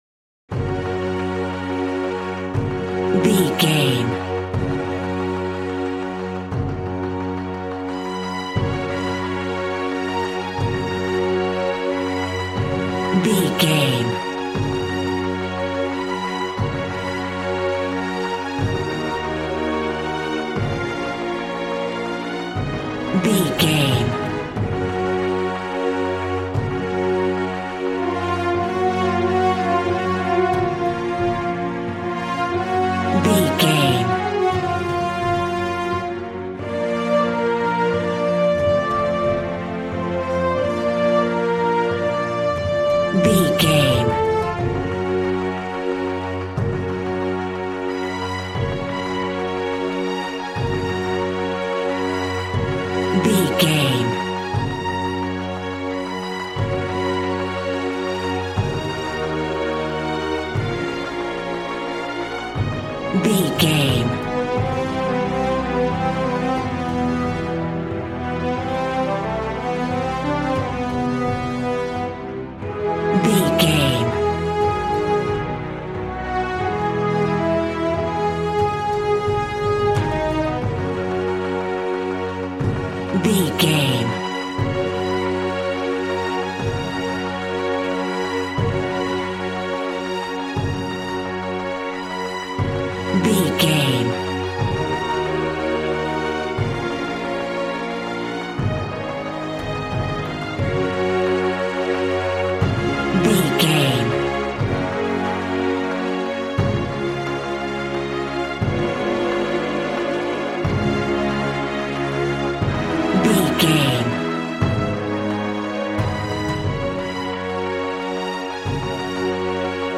Ionian/Major
G♭
strings
violin
brass